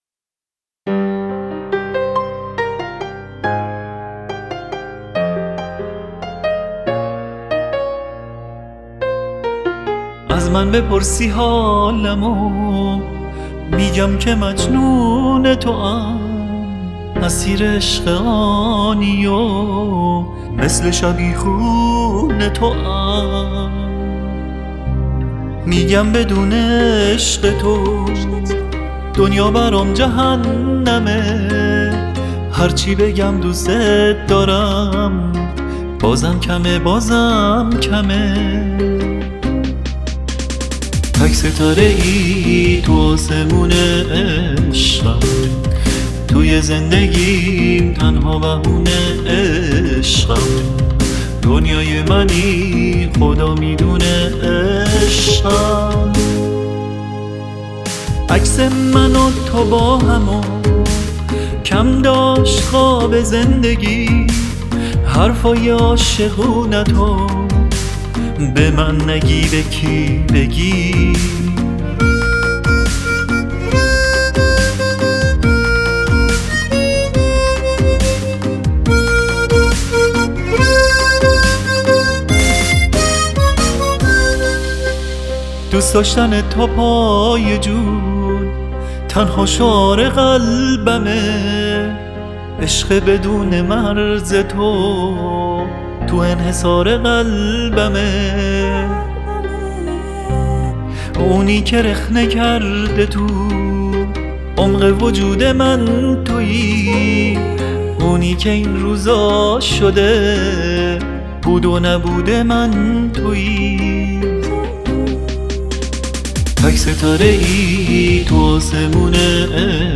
با احساسو ملایم بود